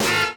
HORN HIT 2.wav